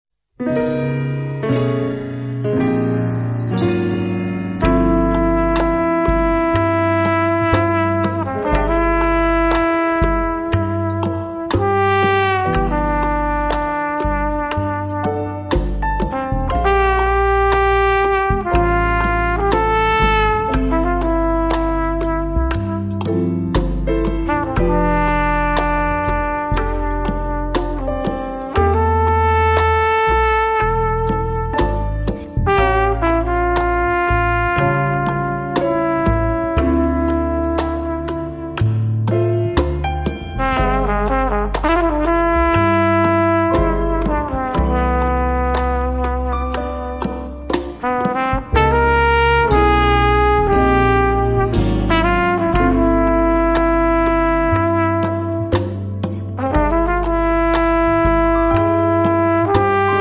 drums, timbales, congas, bongo, guiro, bata & backing vocals
bass & baby bass
percussion
trumpet & flugelhorn, piano, keyboards
trombone
tenor sax
piano
vocals & backing vocals